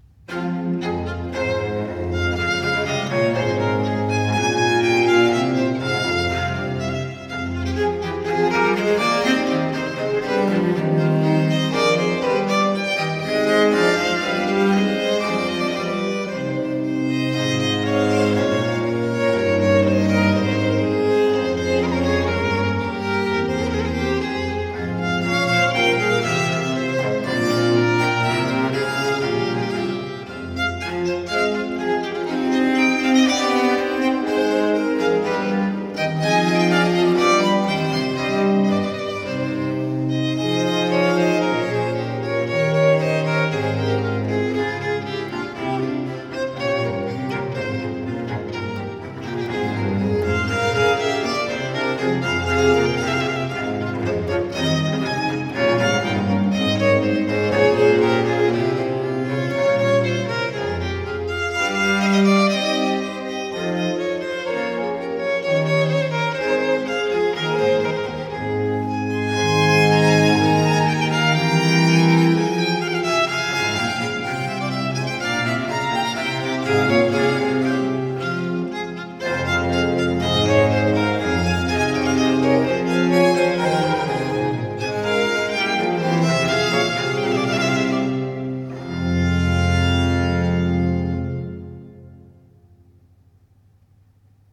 Allegro